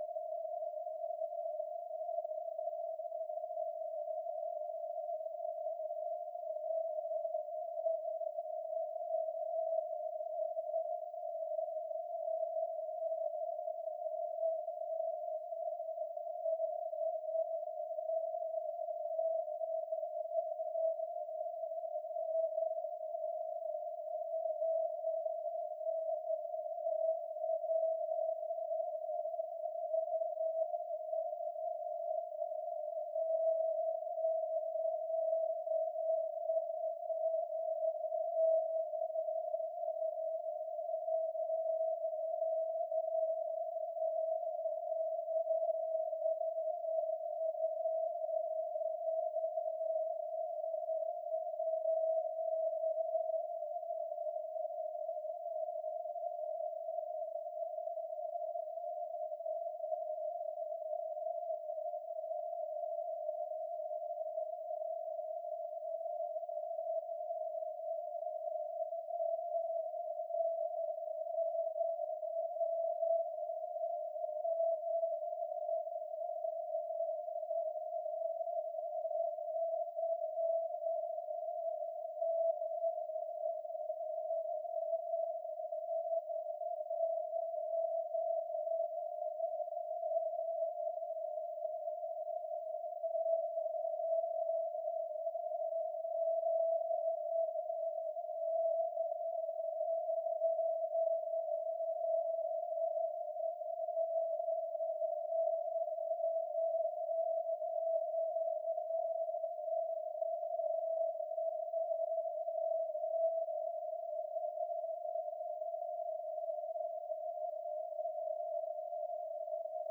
For the first few days we struggled with low signal to noise ratios, which was due to the moon and sun having coincident orbits.
ON0EME as received by our 3 m parabolic dish, 0.5 dB LNA and USRP B210 setup.
Apparently it is quite rare to be able to have audible CW signals from the Moon, at least with a setup such as ours (3 m dish + 0.5 dB LNA).
It is much easier to see the signal, than it is to listen for it.